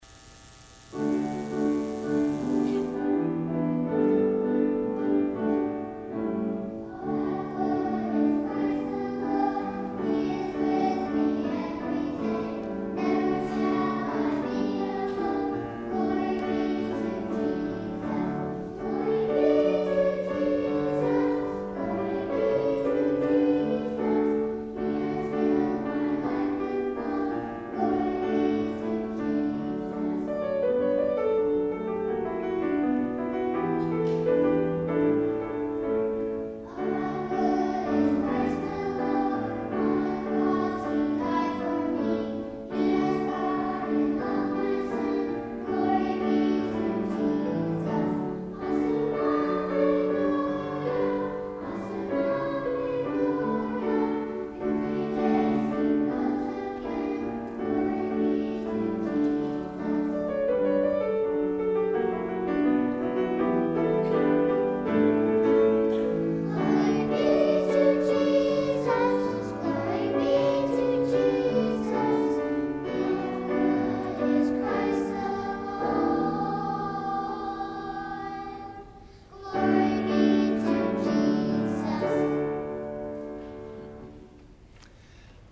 Junior Choir
piano